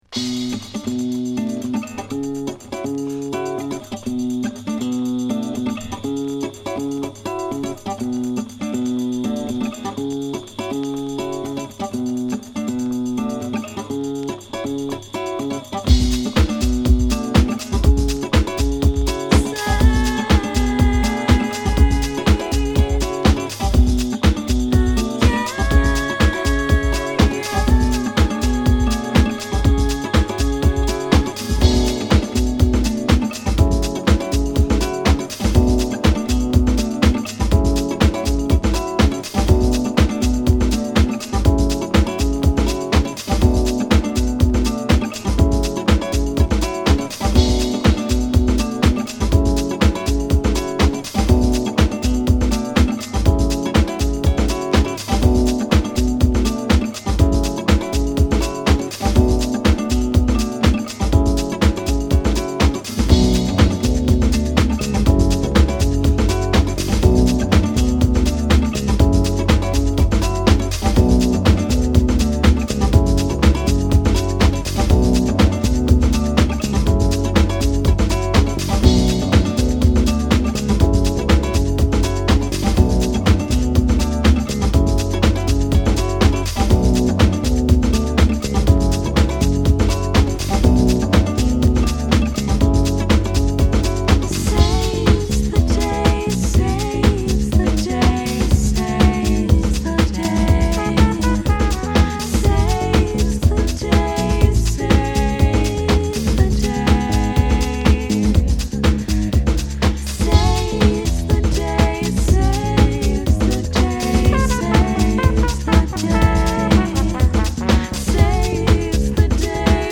クラブ、ダンス